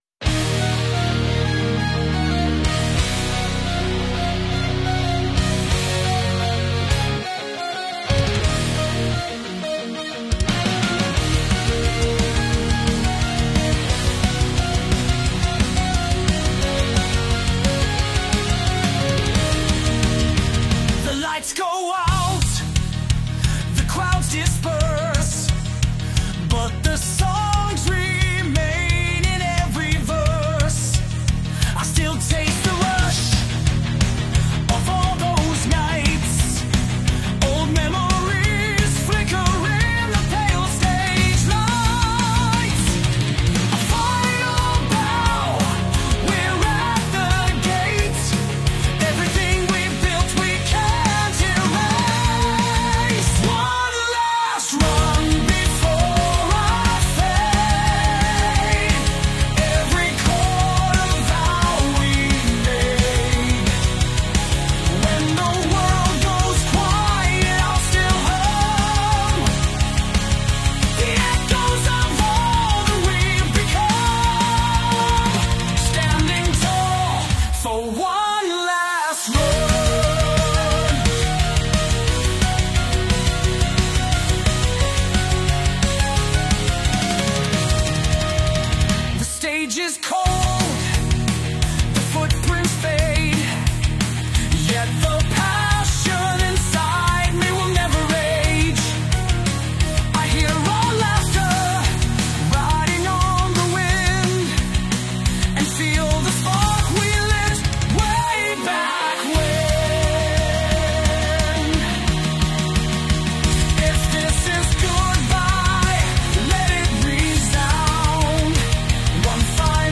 Every journey starts with a rumble—Scout Bobber rolling out.